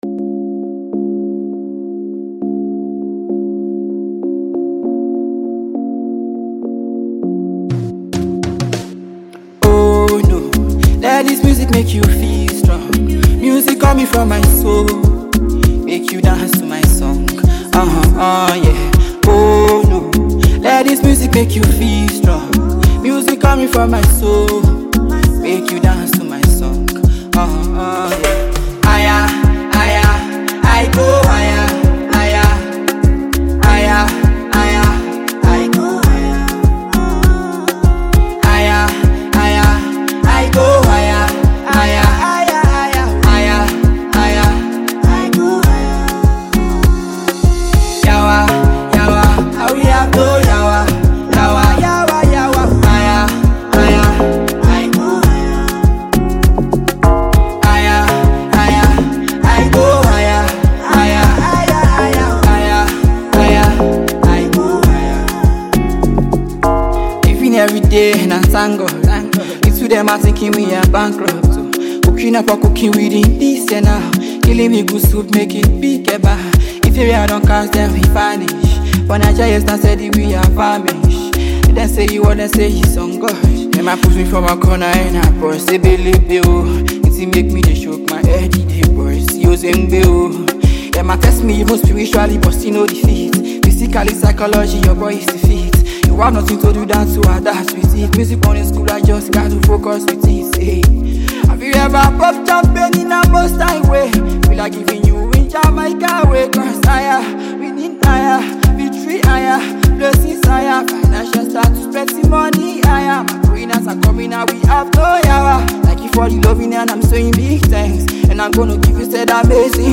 With its infectious beat and motivational lyrics